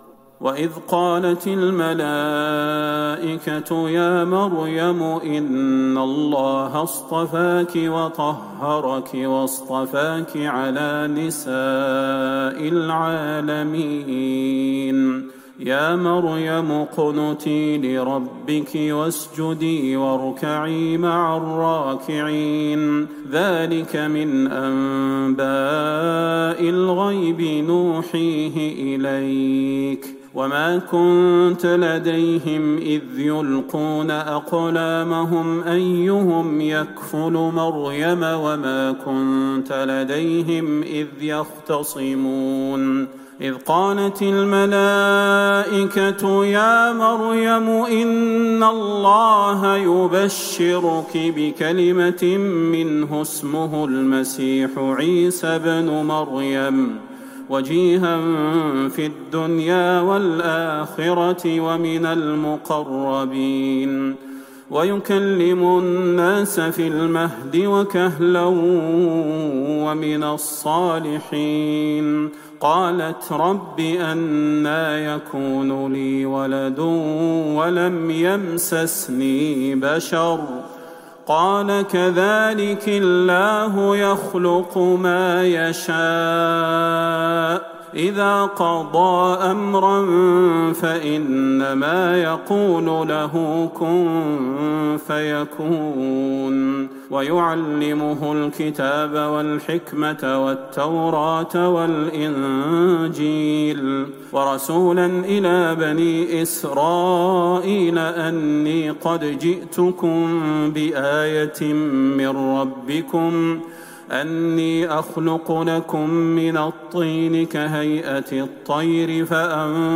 ليلة ٤ رمضان ١٤٤١هـ من سورة آل عمران { ٤٢-٩٢ } > تراويح الحرم النبوي عام 1441 🕌 > التراويح - تلاوات الحرمين